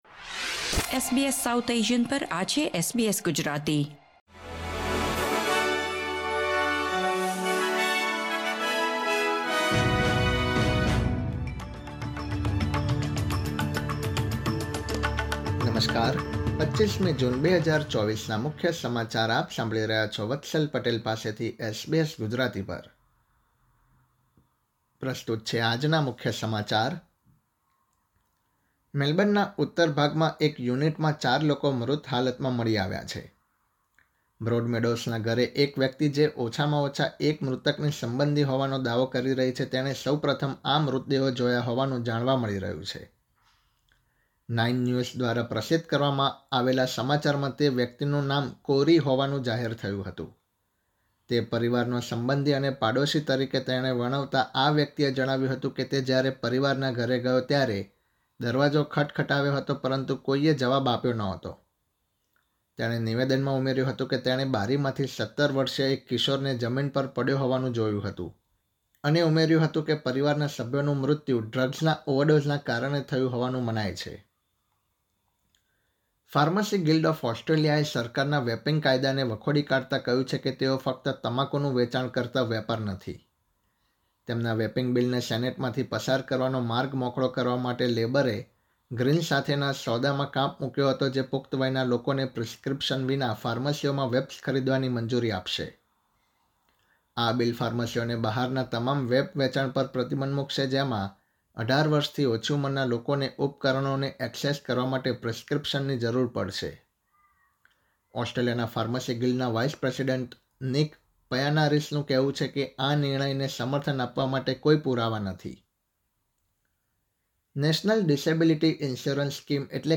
SBS Gujarati News Bulletin 25 June 2024